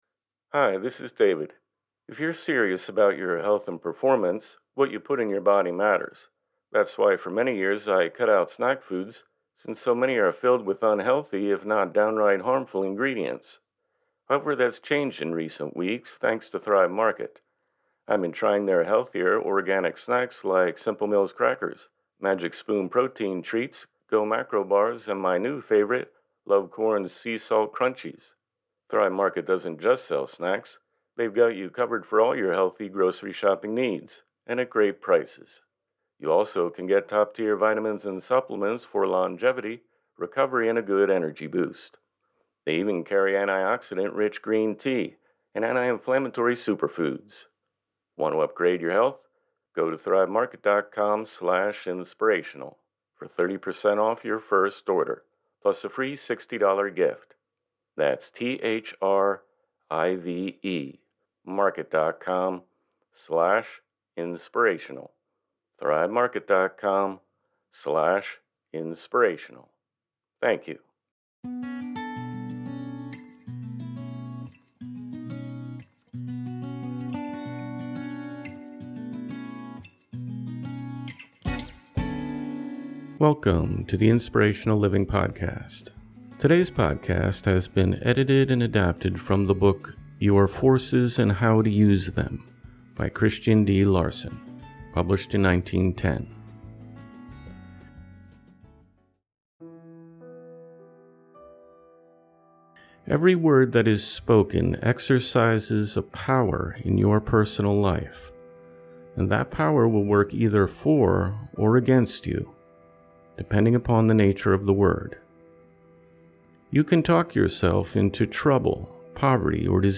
Look no further than the most motivational self-help authors of the past. Inspiring readings from James Allen, Napoleon Hill, Hellen Keller, Booker T. Washington, Khalil Gibran, Marcus Aurelius, and more.